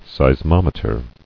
[seis·mom·e·ter]